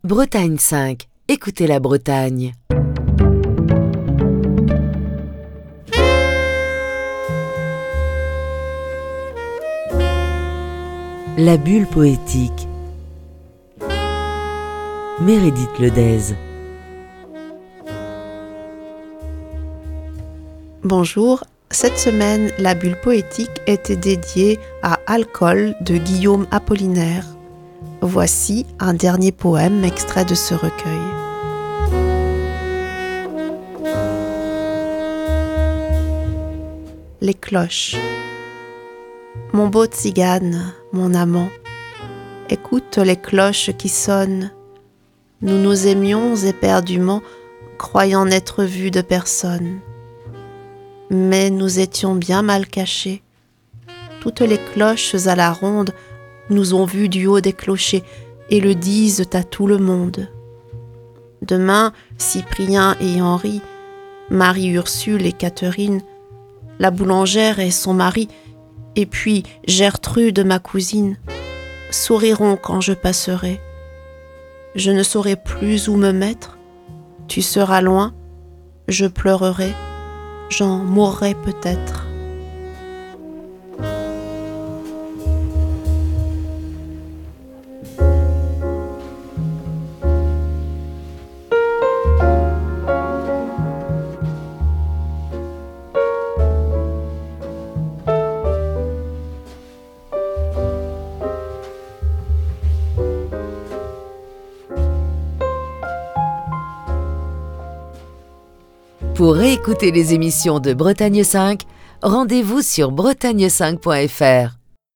lisait des poèmes extraits de "Alcools", célèbre recueil de Guillaume Apollinaire